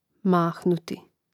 máhnuti mahnuti